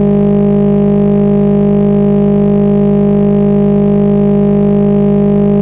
Audio loops click only when exported to Roblox
.ogg files sound perfectly fine in audio editing software (Audacity) and in Windows Media Player, yet when I export those files to Roblox and play them ingame, they click at the loop point, every single time.
I have 12 different engine tracks that all sound perfect in Audacity and Media Player yet on Roblox every single one clicks.